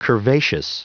Prononciation du mot curvaceous en anglais (fichier audio)
Prononciation du mot : curvaceous
curvaceous.wav